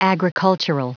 Prononciation du mot agricultural en anglais (fichier audio)
Prononciation du mot : agricultural